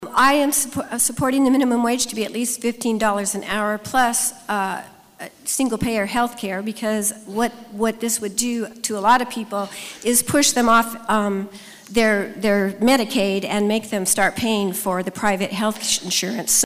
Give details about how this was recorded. They outlined those reasons at the Marion Cultural and Civic Center Wednesday during the first of two debates.